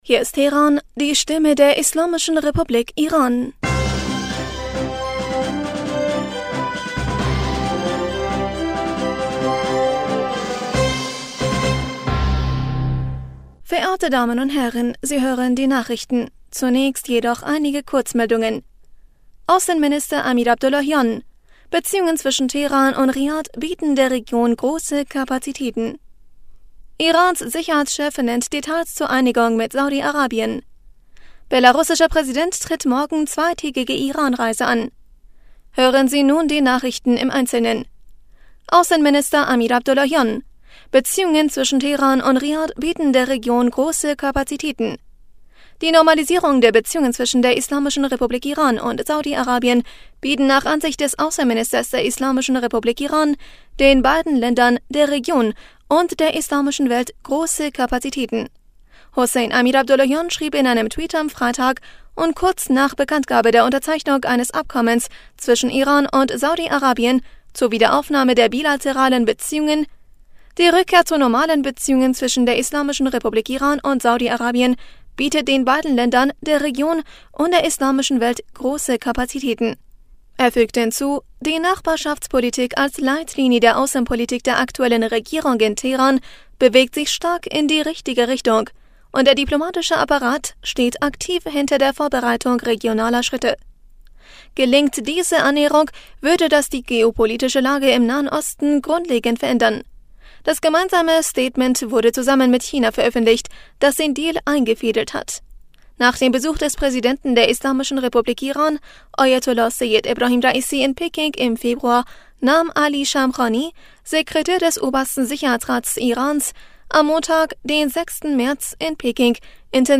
Nachrichten vom 11. März 2023